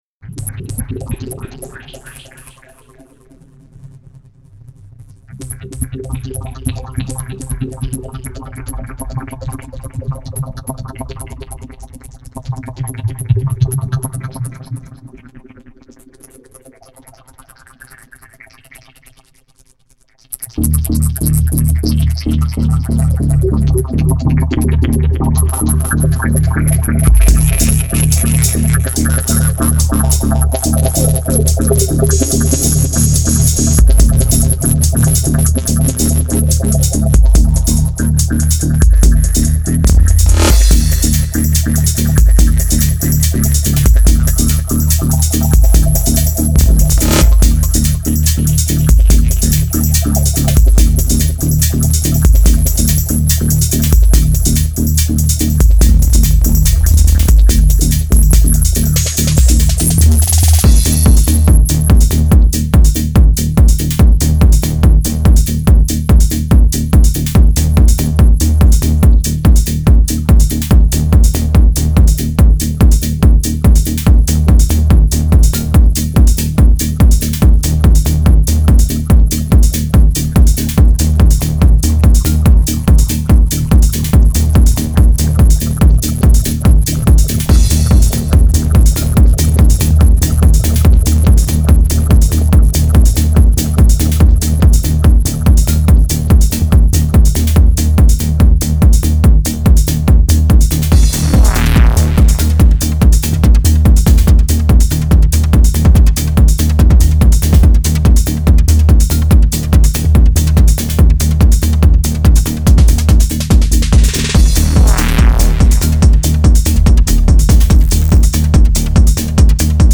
Жанр: TECHNO